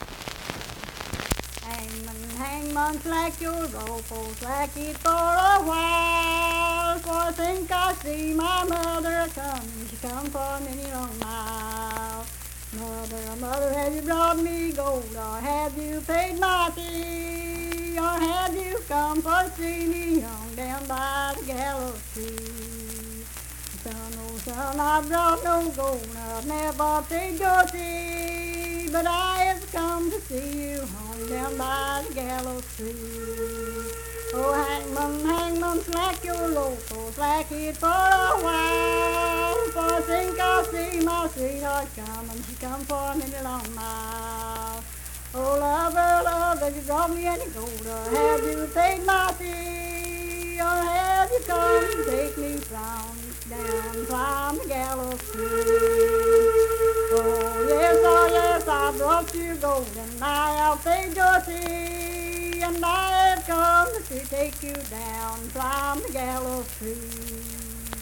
Unaccompanied vocal music performance
Verse-refrain, 8(4).
Voice (sung)
Kirk (W. Va.), Mingo County (W. Va.)